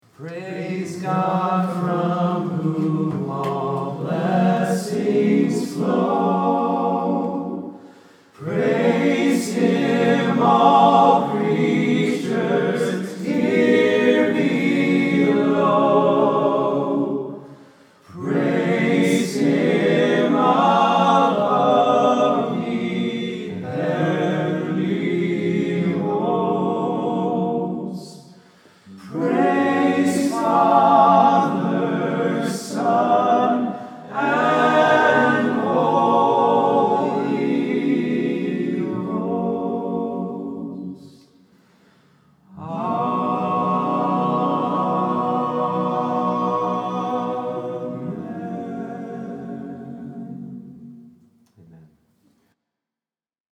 During our gathering last week in Atlanta, we learned from each other, learned from the amazing team at Mount Paran, and came away with a huge dose of encouragement. I’ll be sharing more of what we learned in the weeks to come, but for now, I’ll share this short little audio clip of a room full of worship leaders and choir directors from all over the country, who after a long day of wrestling with some big questions, lifted their voices together to sing “Praise God from whom all blessings flow”.
6-doxology.mp3